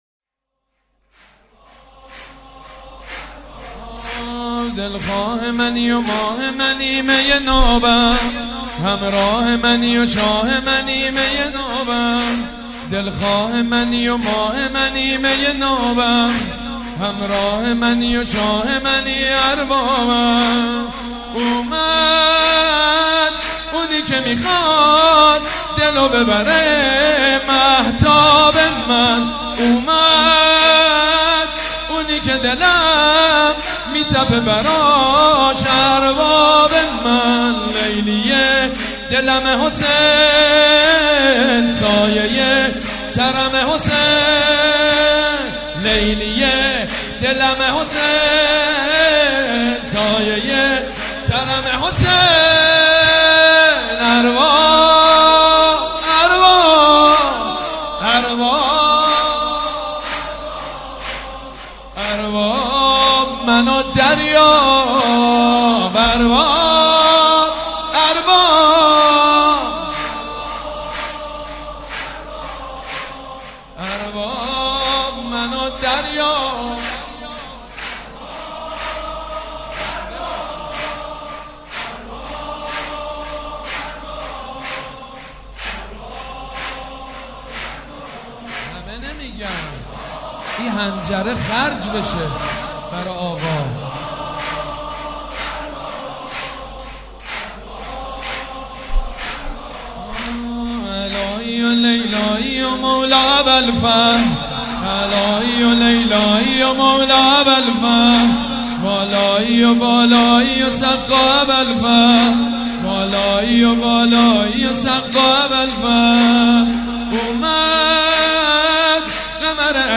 سرود: دلخواه منی و ماه منی